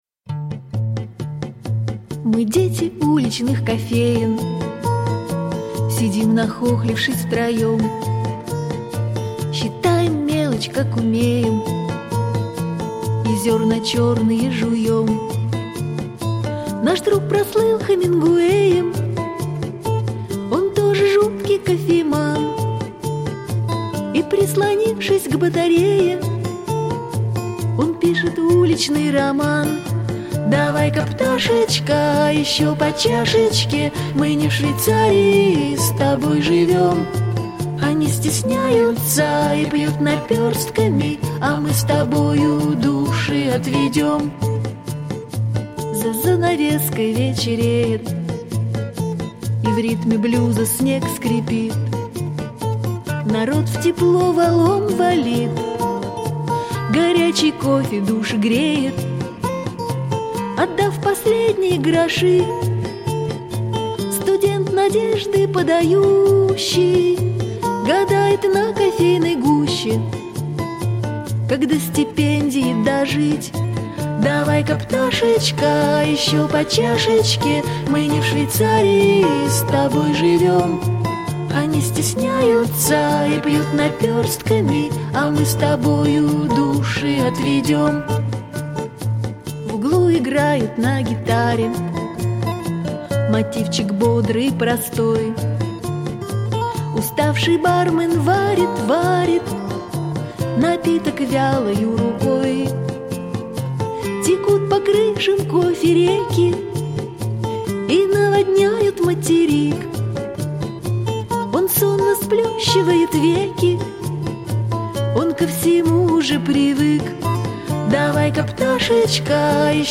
под гитару